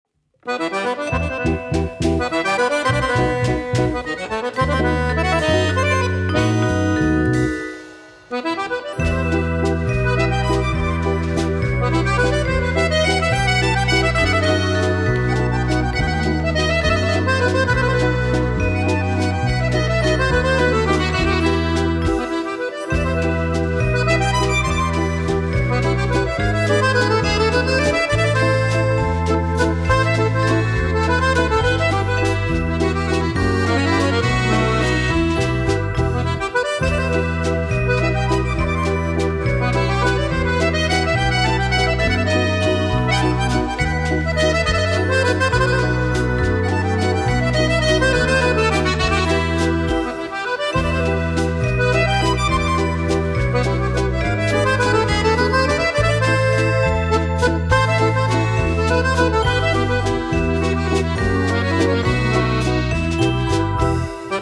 Hea akordionimuusika huviline